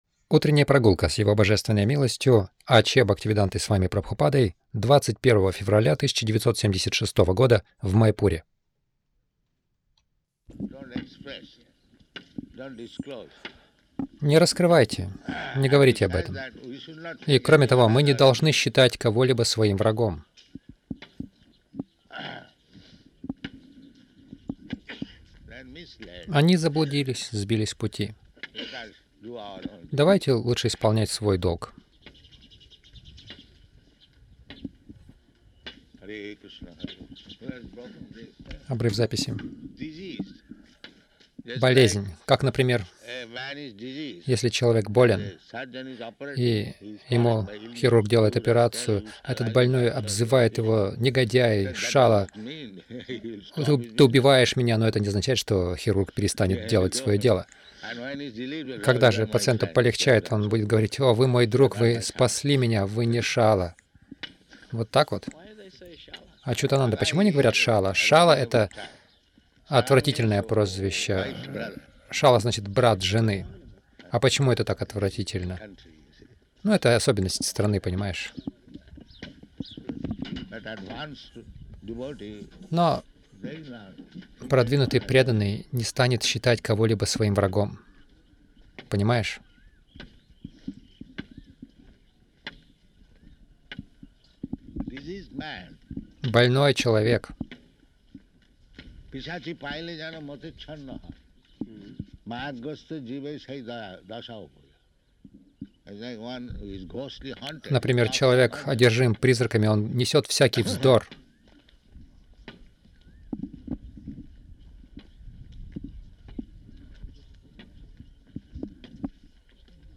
Милость Прабхупады Аудиолекции и книги 21.02.1976 Утренние Прогулки | Маяпур Утренние прогулки — У преданного нет врагов Загрузка...